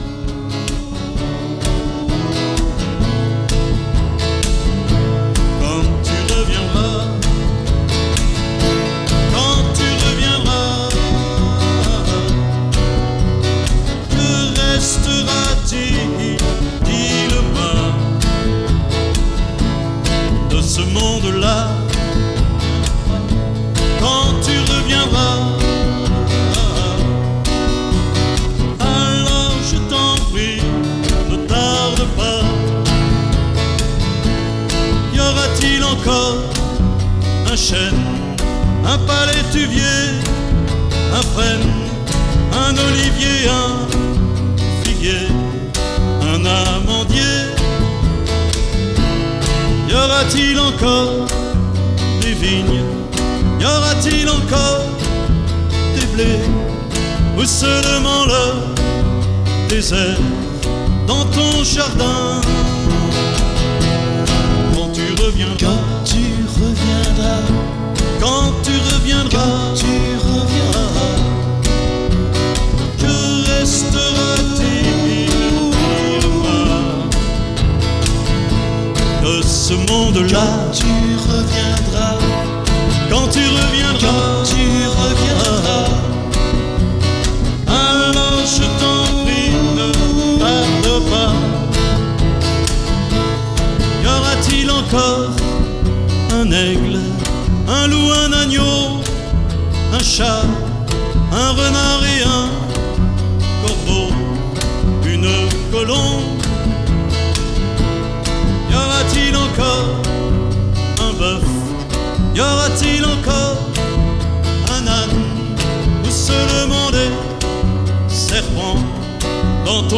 Enregistrement en public, en l’église protestante d’Horbourg-Wihr (68), le 21 septembre 1997.
chant et guitare
contrebasse